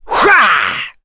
Fluss-Vox_Attack7.wav